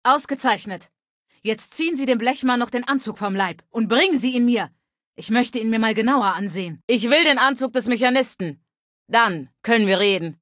Datei:Femaleadult01default ms02 greeting 000b29a8.ogg